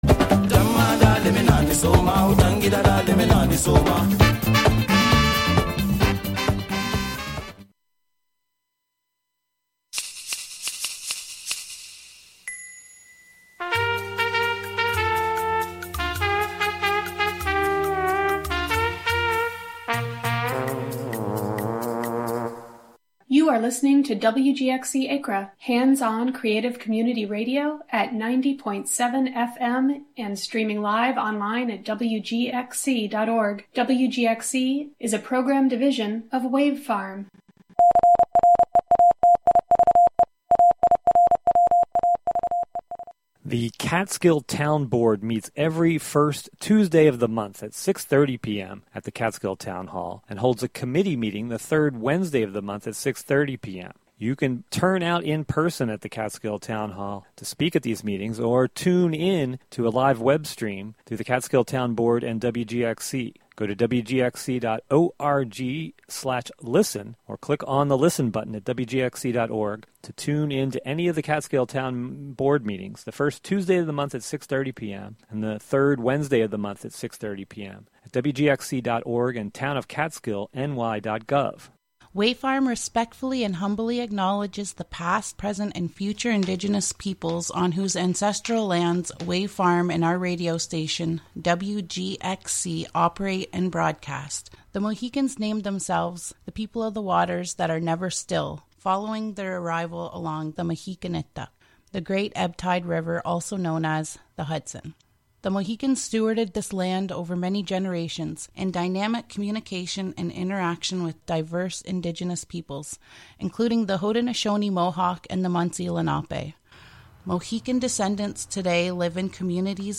On this monthly program, “La Ville Inhumaine” (The Inhuman City), you will hear music, found sounds, words, intentional noise, field recordings: altogether, all at once.